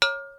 ding_long
clang ding metallic sound effect free sound royalty free Sound Effects